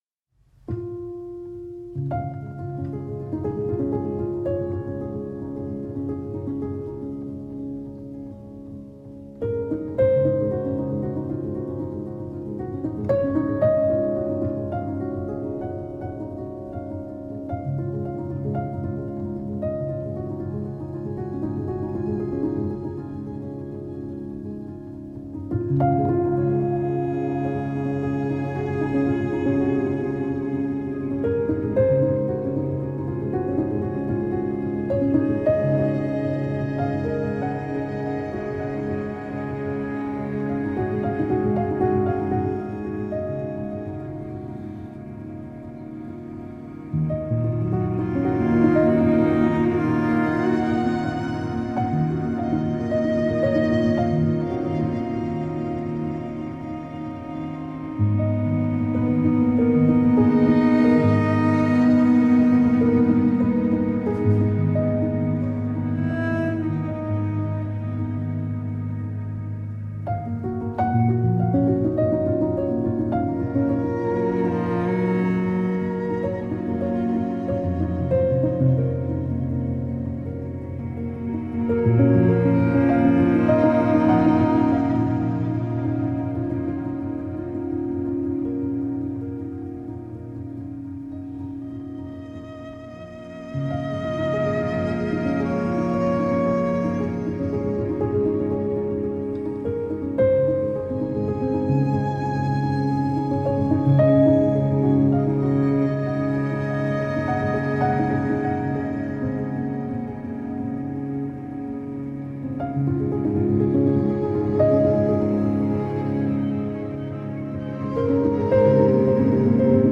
for piano violin cello